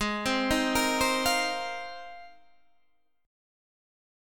Ab6add9 Chord